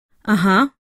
Звуки согласия
Все файлы записаны четко, без фоновых шумов.